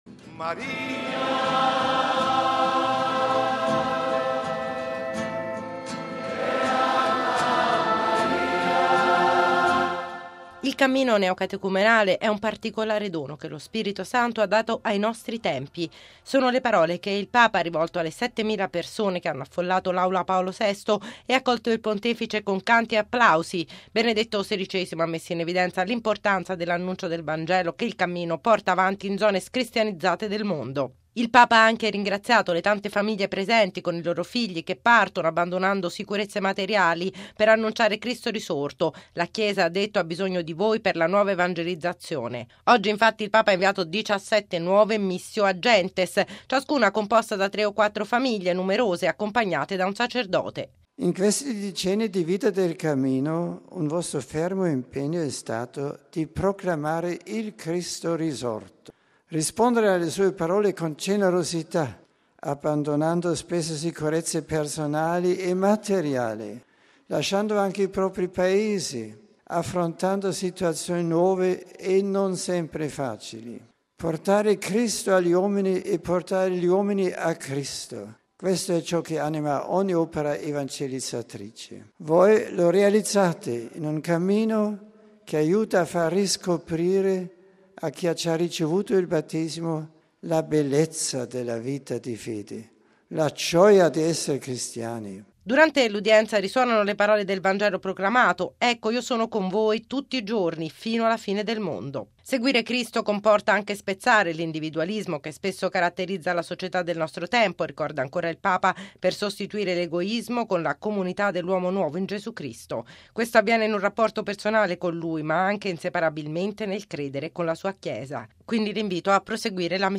Sono le parole che il Papa ha rivolto alle 7mila persone che hanno affollato l’Aula Paolo VI e accolto il Pontefice con canti ed applausi.